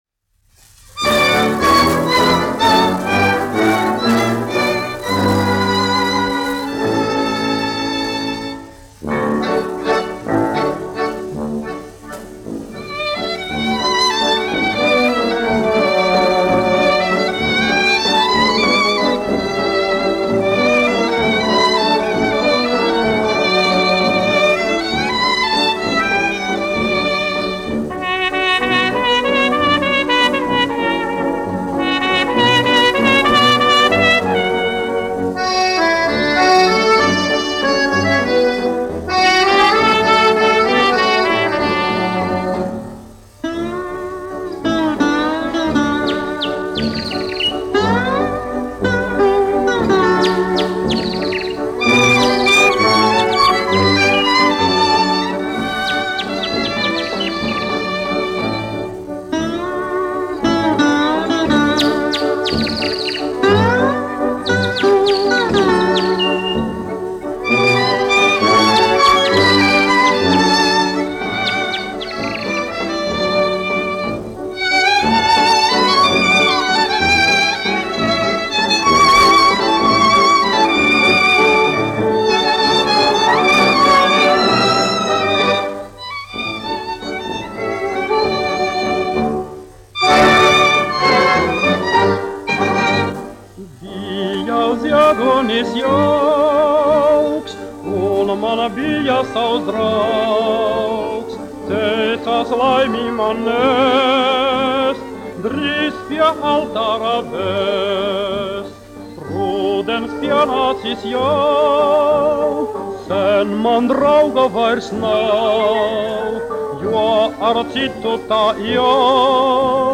1 skpl. : analogs, 78 apgr/min, mono ; 25 cm
Valši
Popuriji
Skaņuplate